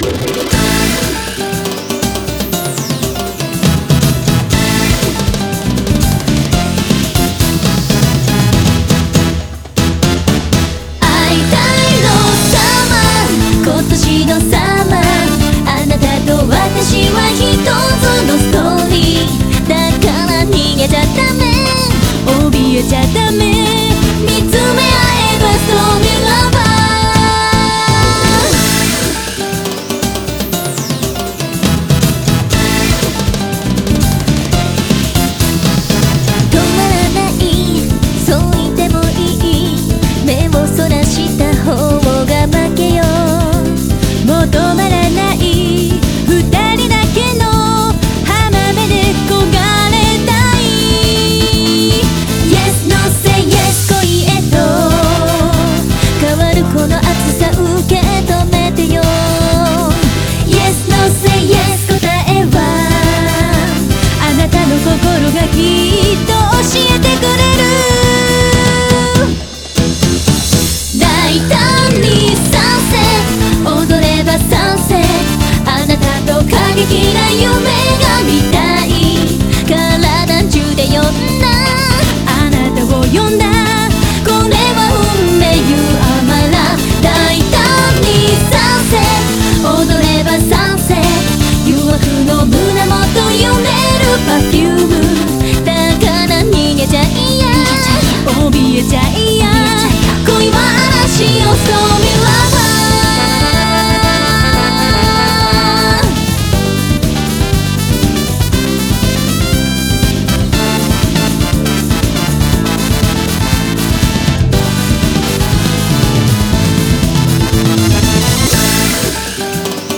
BPM120
Audio QualityPerfect (High Quality)
Comments[ROMANTIC SAMBA]